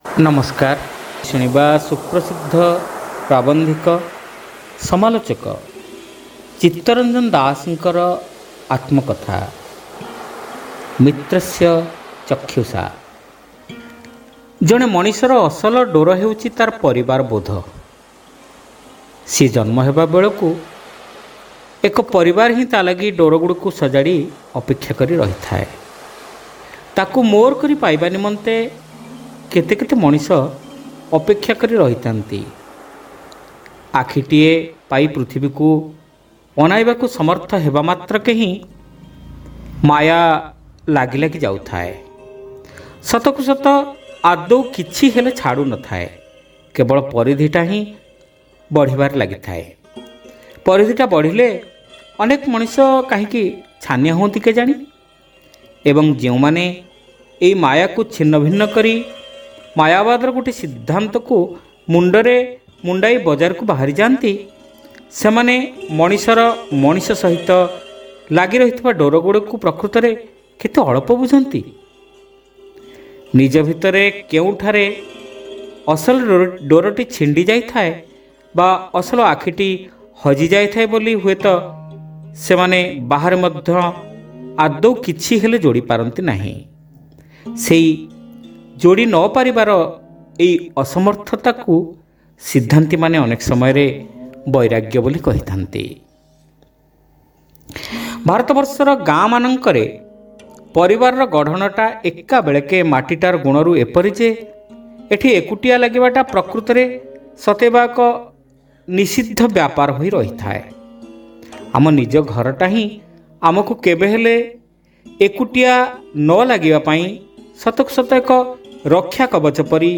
ଶ୍ରାବ୍ୟ ଗଳ୍ପ : ମିତ୍ରସ୍ୟ ଚକ୍ଷୁସା (ପ୍ରଥମ ଭାଗ)